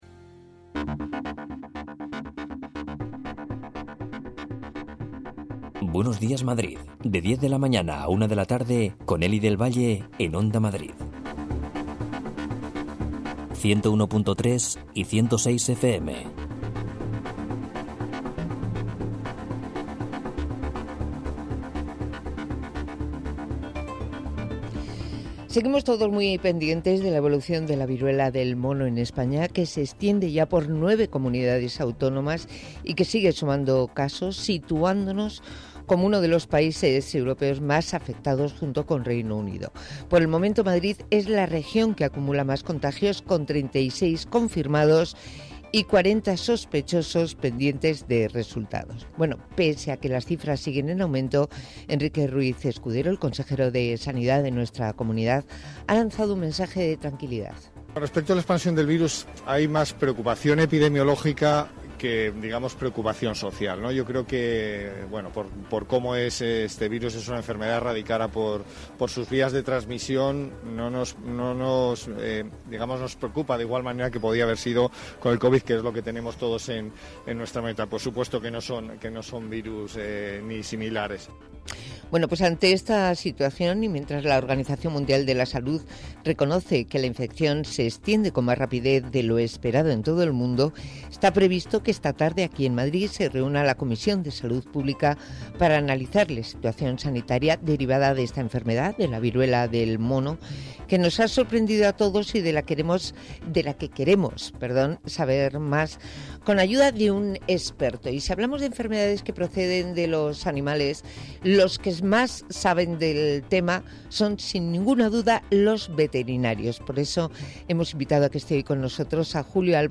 EN ONDA MADRID